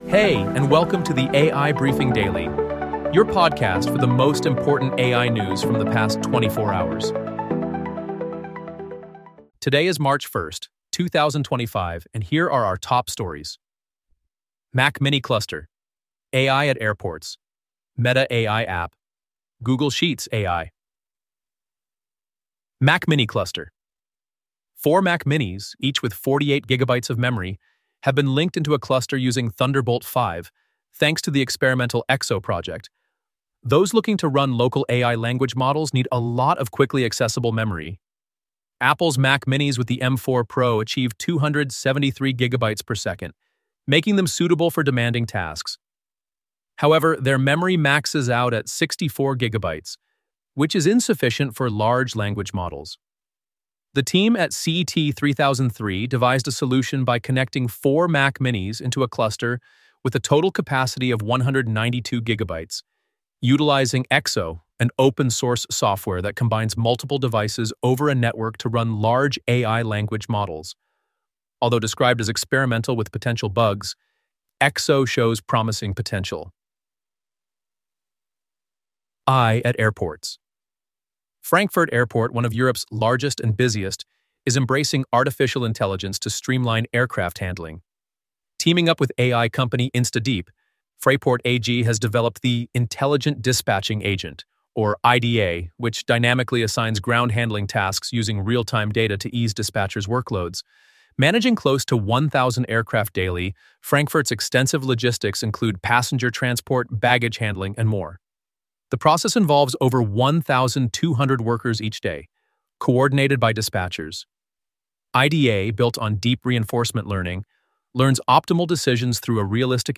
Would you like to create your own AI-generated and 100% automated podcast on your chosen topic?